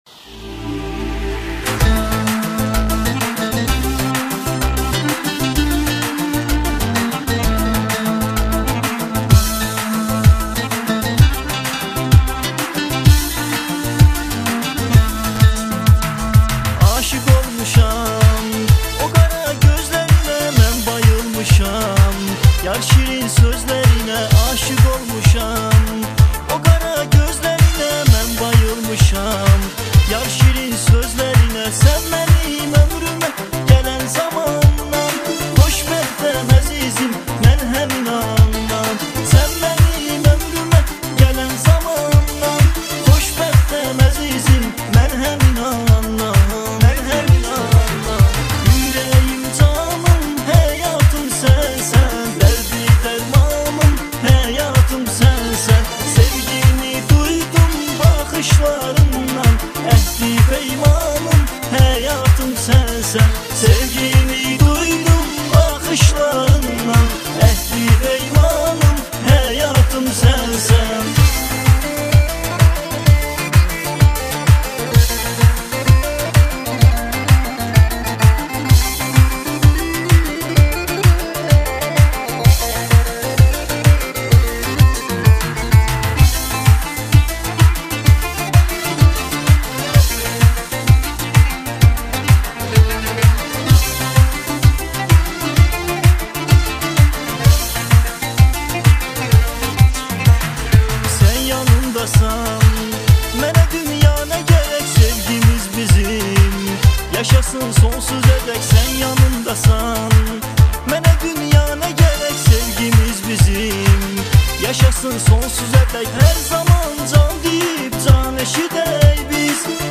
Жанр: Турецкая музыка